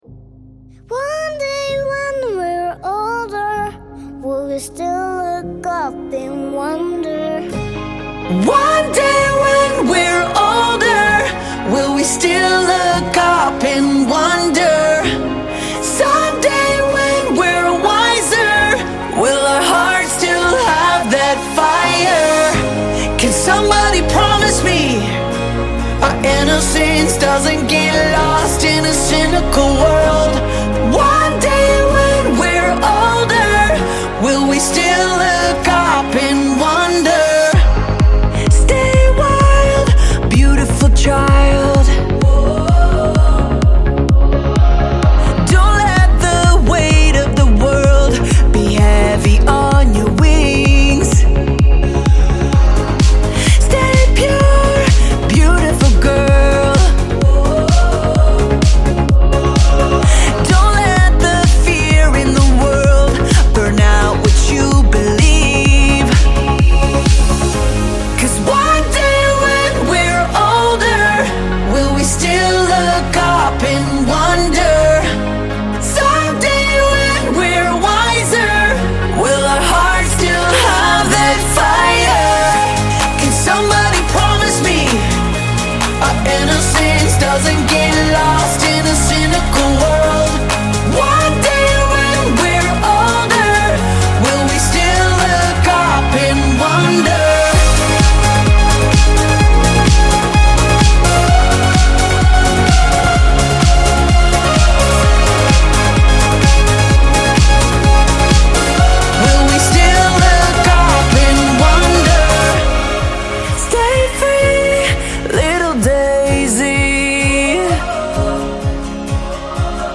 Genre: Christian